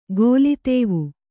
♪ gōli tēvu